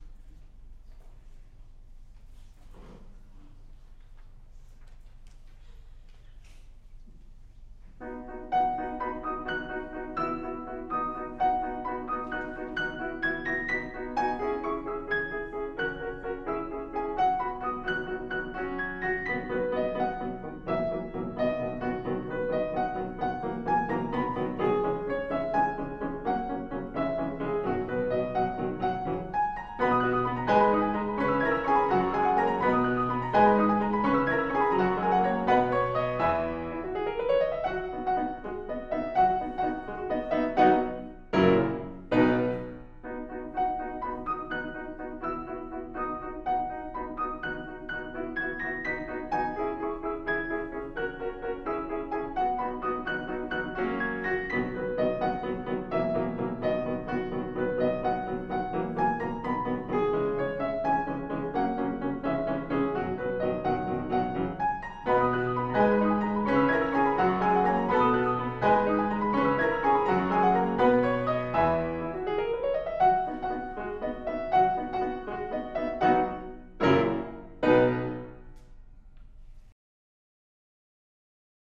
Love Story for Two Pianos
Duet / 2010
Movement 1: Bagatelle